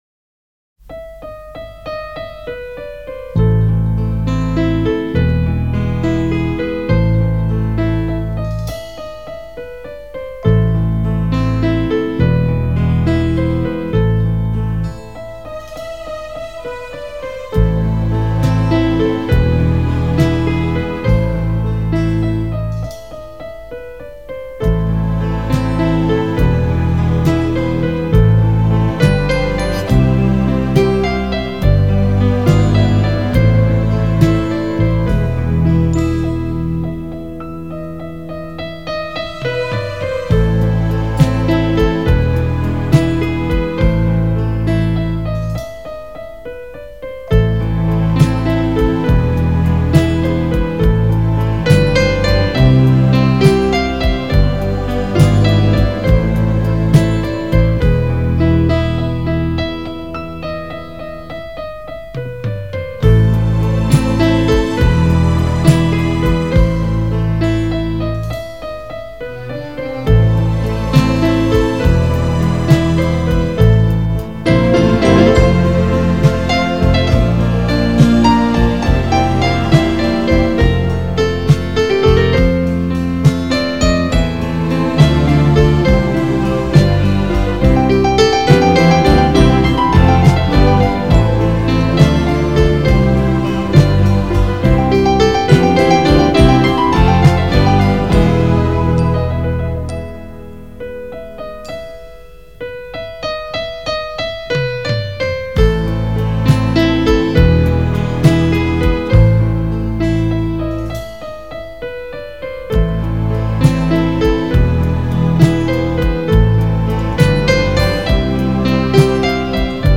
경음악
장르: Pop